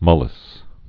(mŭlĭs), Kary Banks 1944-2019.